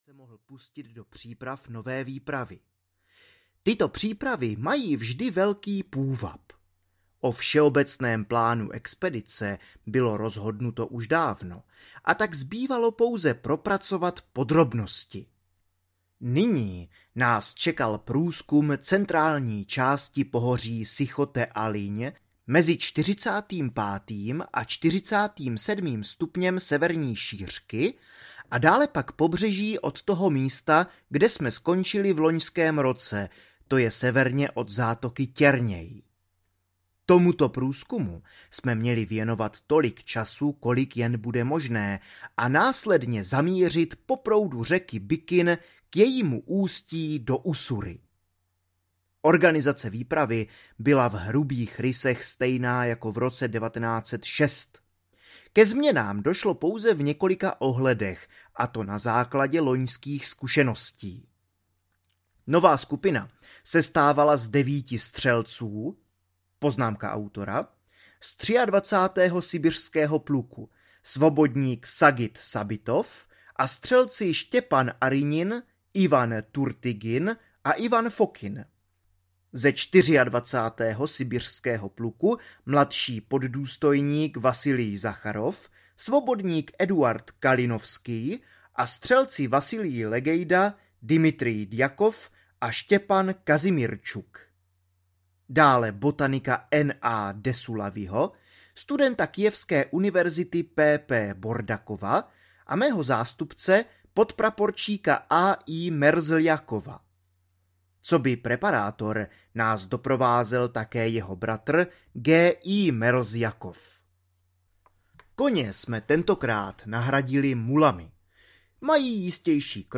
Děrsu Uzala audiokniha
Ukázka z knihy